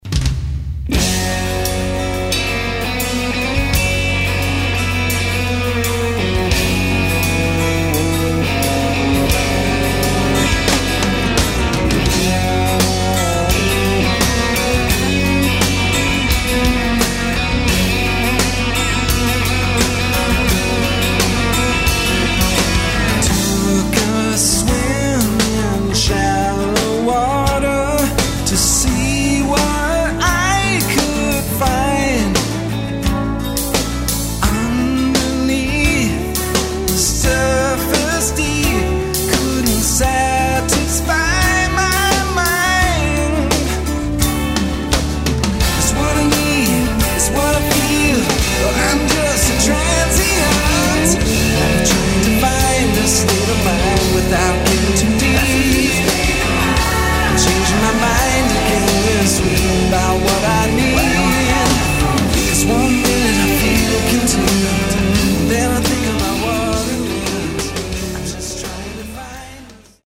Drums and Vocals
Guitars, Bass and Vocals
A cool modern rock track